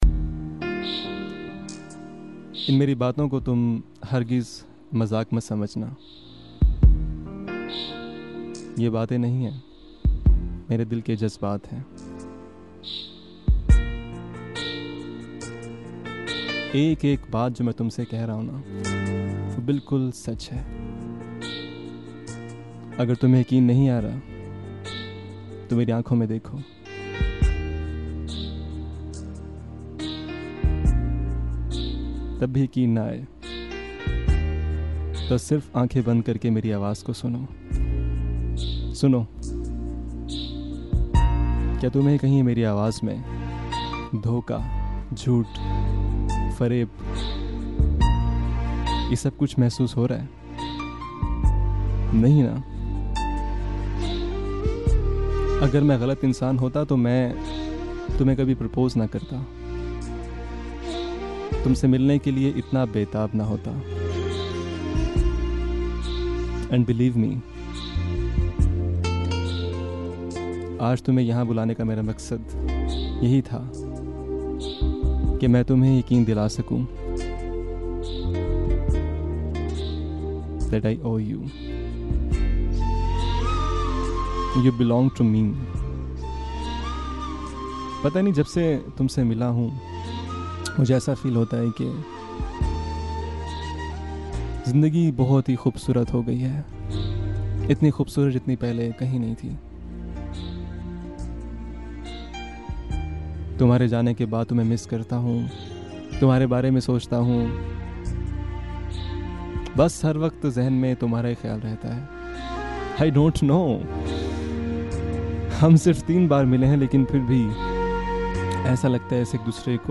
in SRK Style